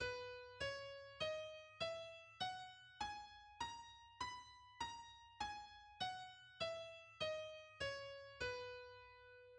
Si maior
Si, Dó♯, Ré♯, Mi, Fá♯, Sol♯, Lá♯, Si